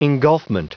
Prononciation du mot engulfment en anglais (fichier audio)
Prononciation du mot : engulfment